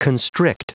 Prononciation audio / Fichier audio de CONSTRICT en anglais
Prononciation du mot : constrict